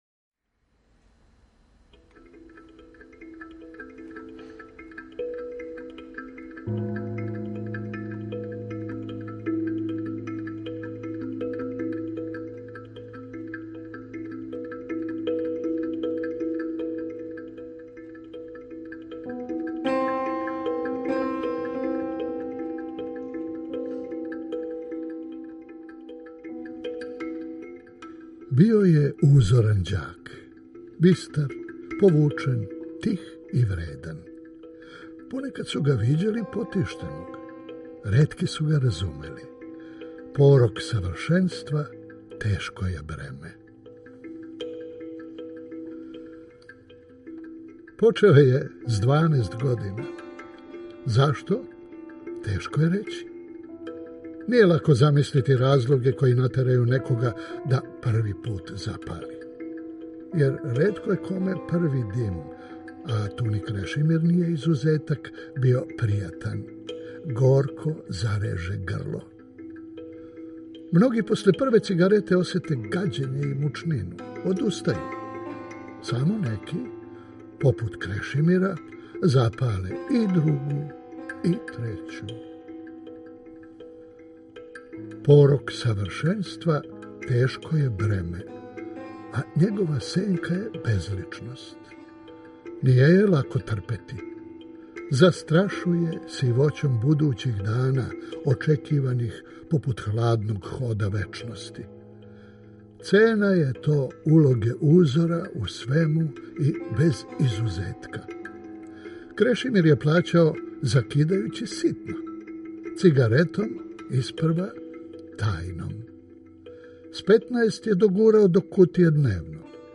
Драмски програм: Антун Павешковић: Остало је (премијера)